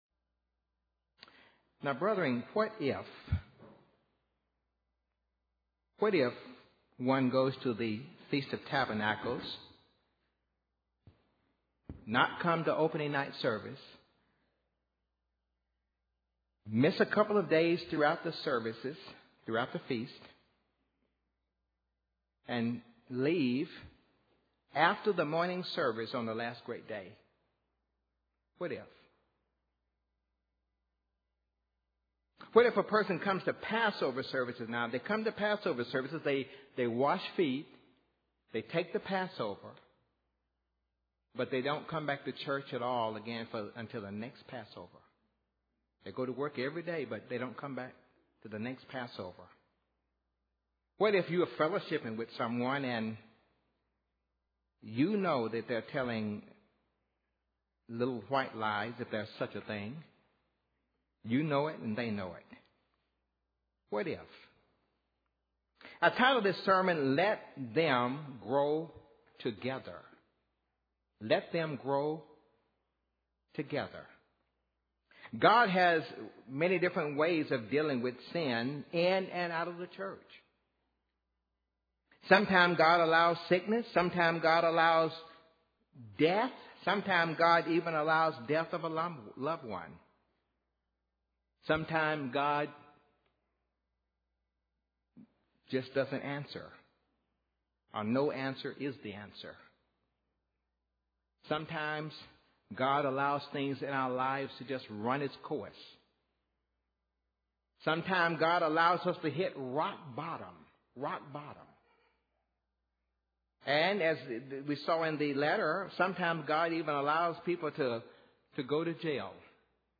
Given in Nashville, TN
UCG Sermon Studying the bible?